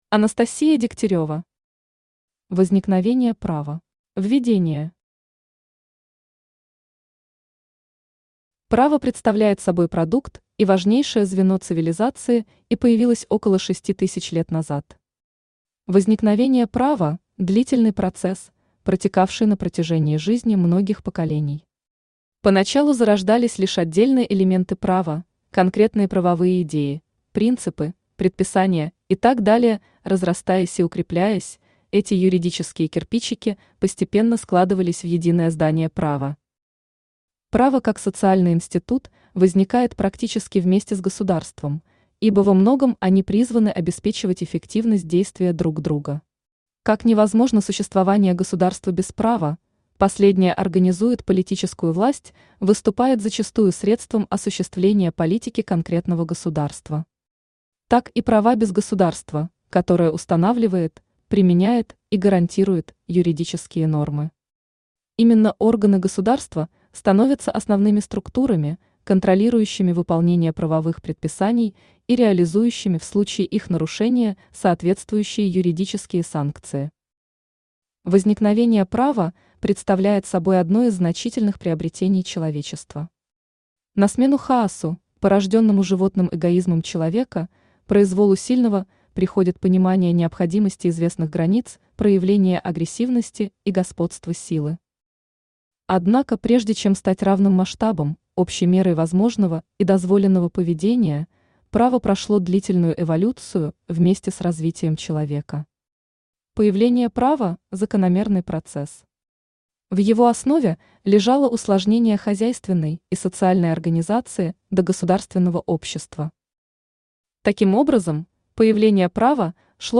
Аудиокнига Возникновение права | Библиотека аудиокниг
Aудиокнига Возникновение права Автор Анастасия Александровна Дегтярева Читает аудиокнигу Авточтец ЛитРес.